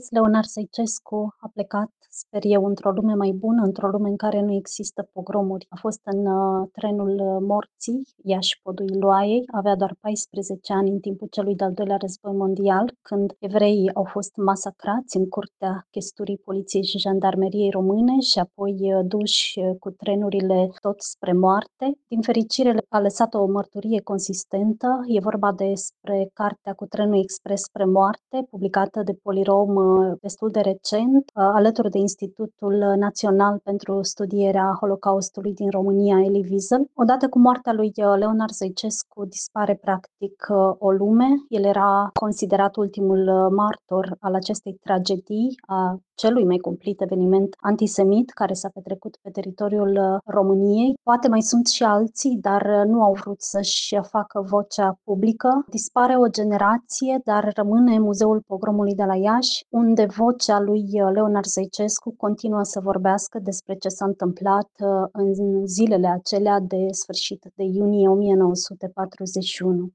Într-un comentariu pentru postul nostru de radio